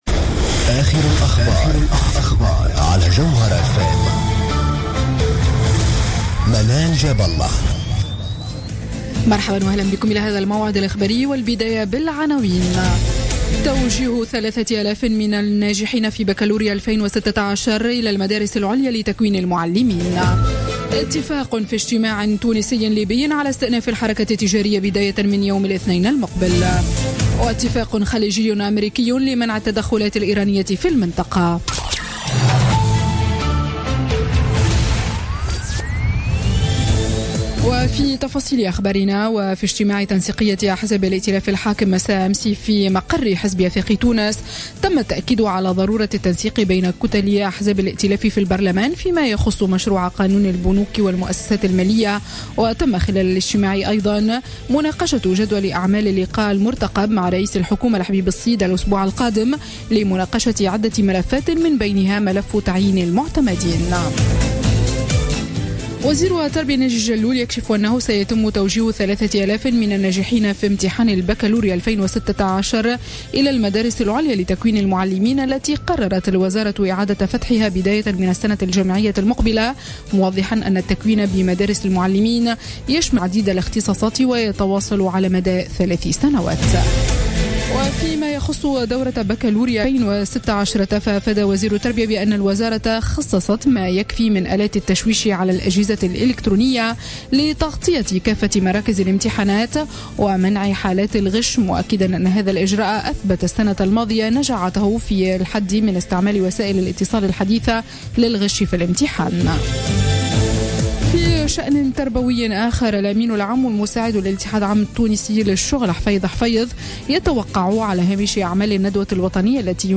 نشرة أخبار منتصف الليل ليوم الجمعة 22 أفريل 2016